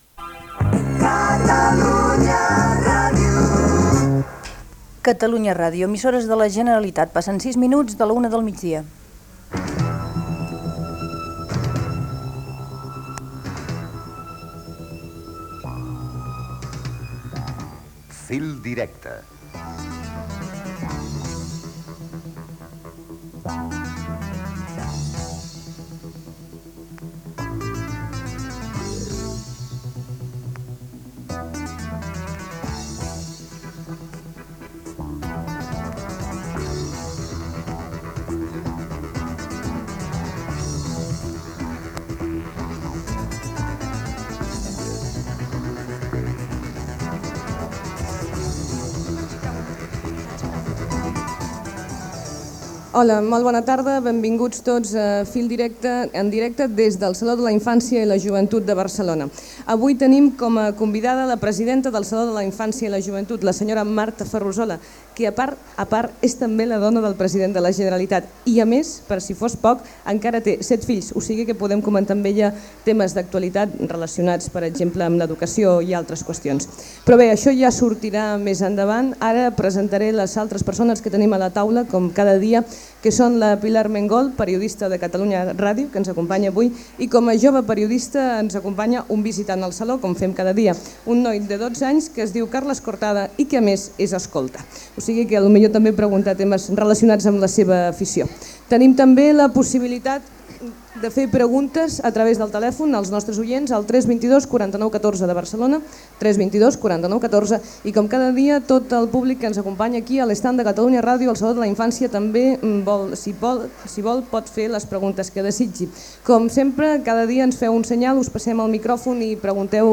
Gènere radiofònic Participació